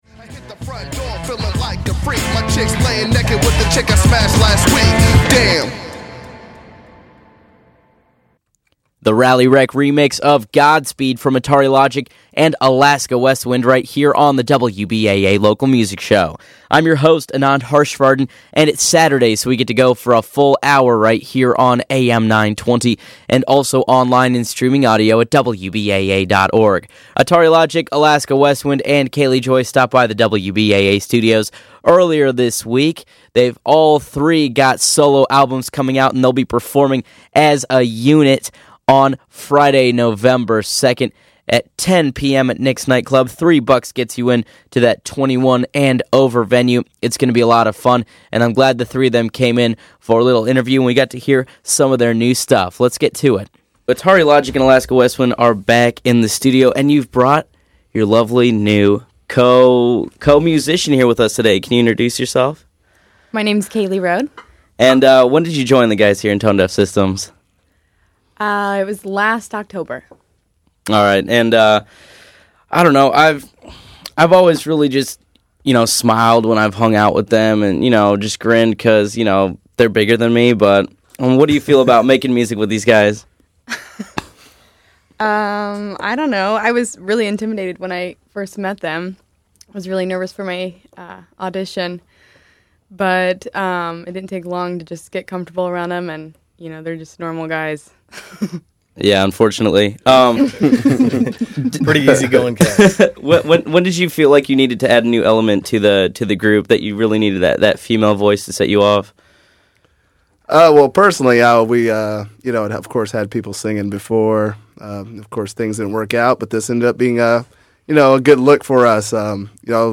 wbaainterview2.mp3